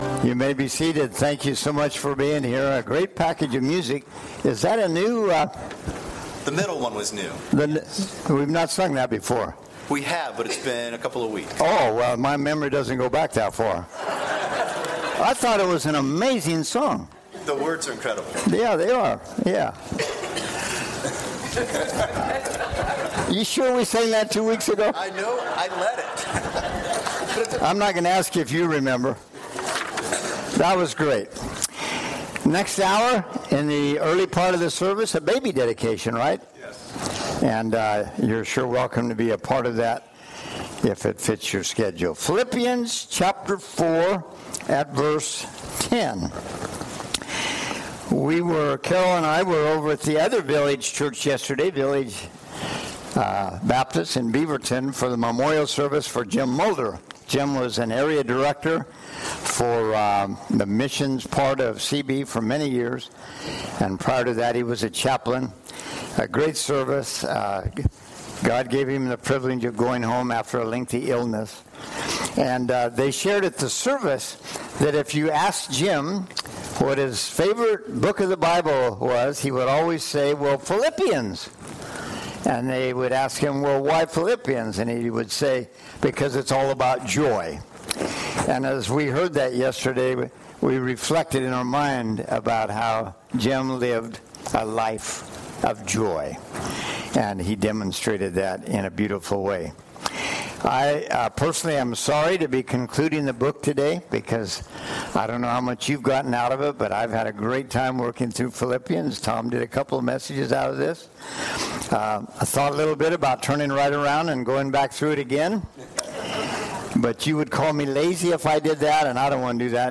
Sermon-8-25-19.mp3